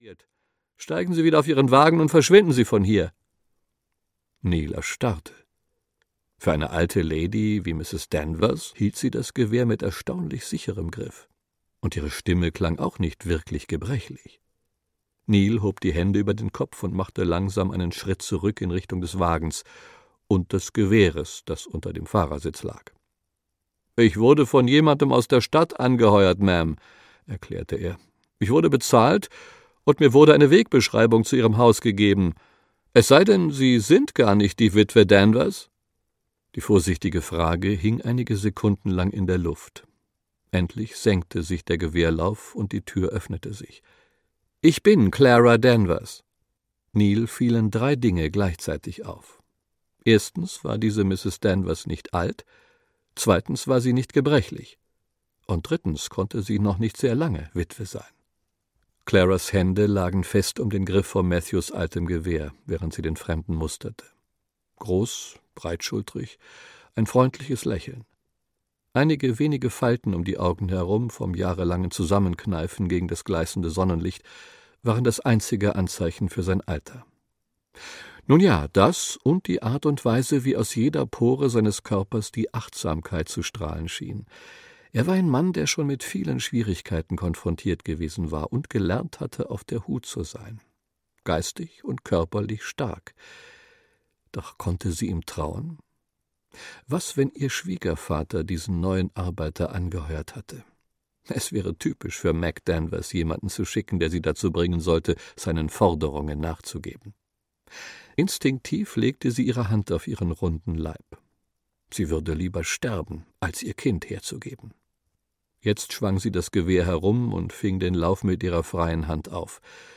Ein Job für Neill Archer - Karen Witemeyer - Hörbuch